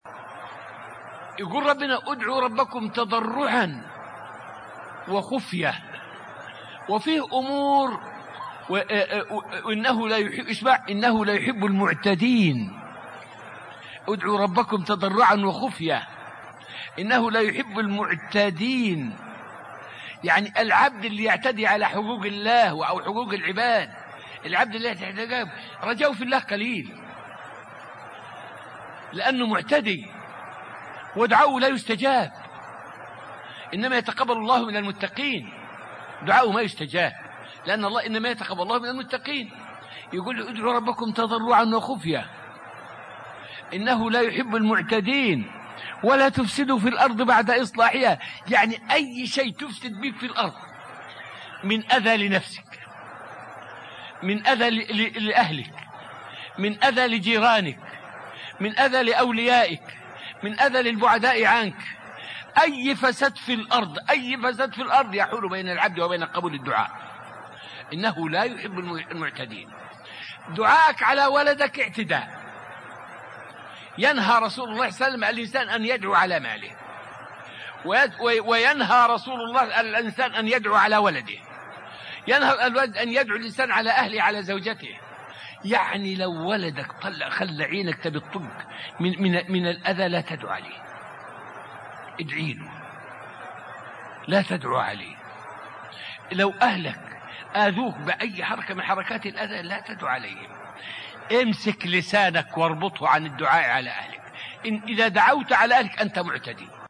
فائدة من الدرس الواحد والعشرون من دروس تفسير سورة البقرة والتي ألقيت في المسجد النبوي الشريف حول الدعاء على الأهل والأولاد.